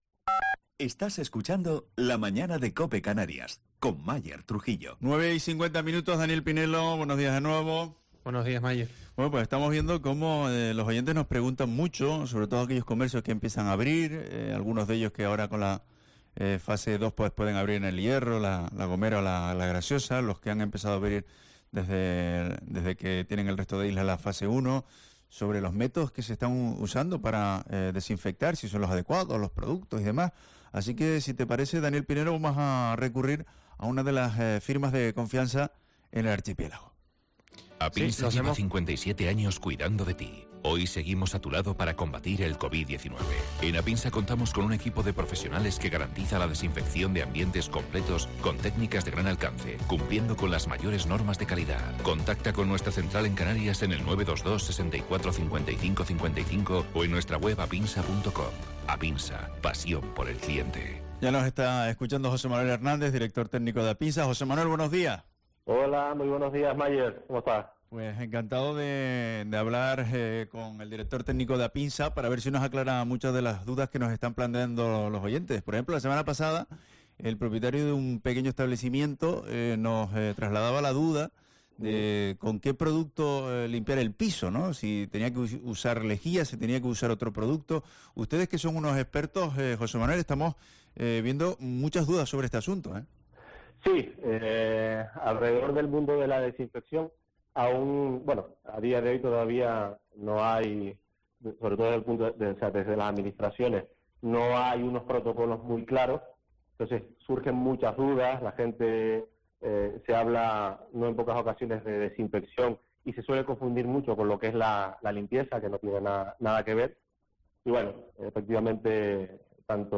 pasaba por los micrófonos de COPE Canarias